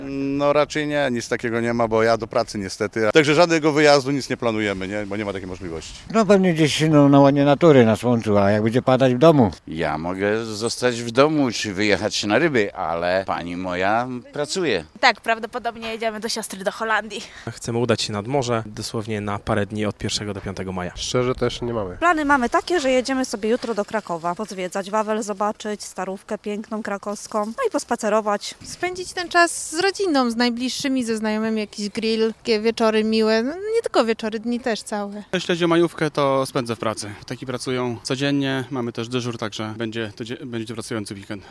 Majówka [SONDA]
W związku z tym zapytaliśmy zielonogórzan czy mają plany na weekend majowy: